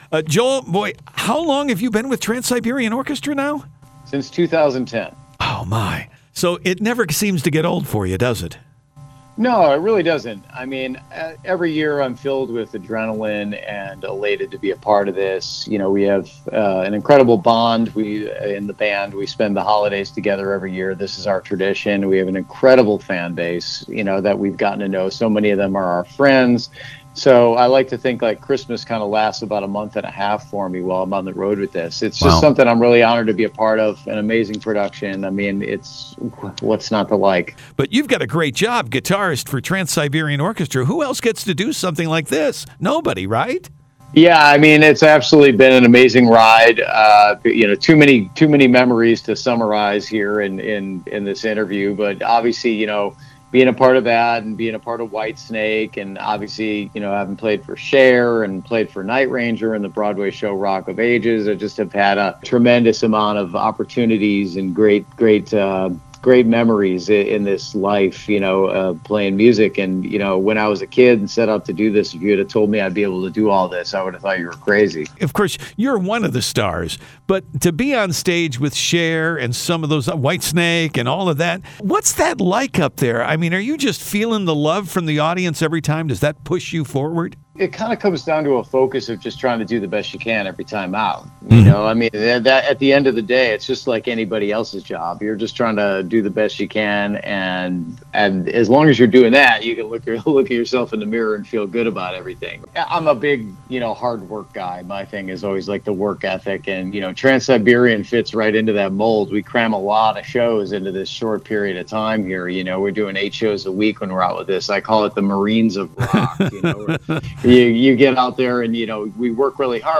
JOEL HOEKSTRA – INTERVIEW https